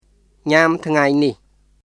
[ニャム・トゥガイ・ニヒ　ɲam tʰŋai nih]